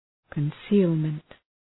concealment.mp3